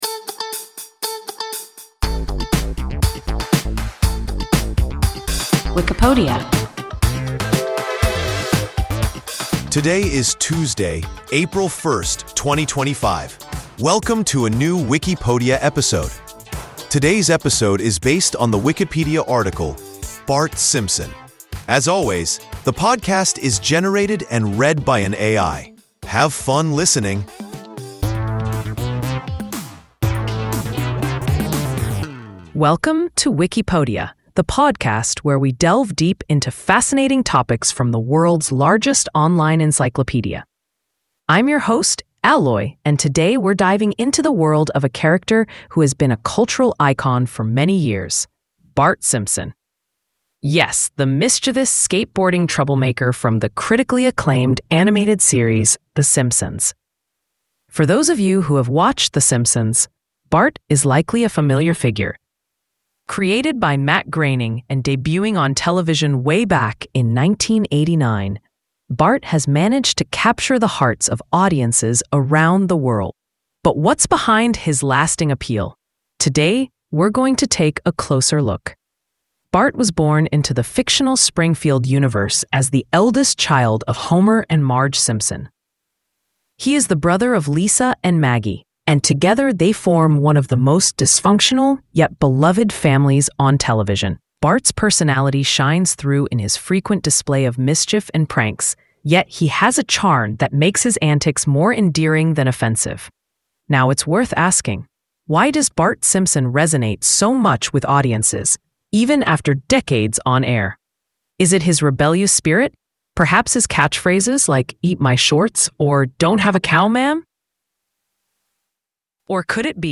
Bart Simpson – WIKIPODIA – ein KI Podcast
Wikipodia – an AI podcast